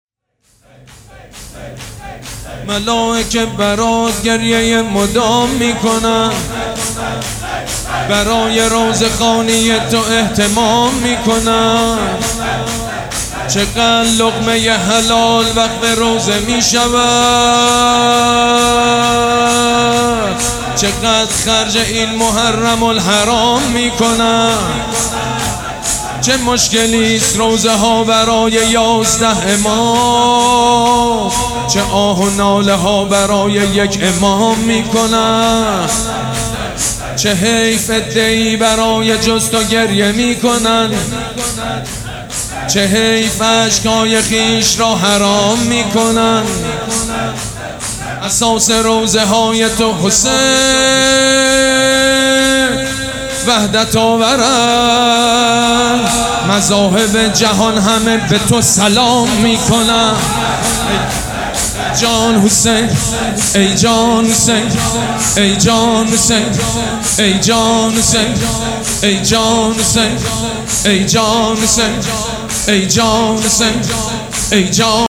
شور
حاج سید مجید بنی فاطمه
مراسم عزاداری شب اول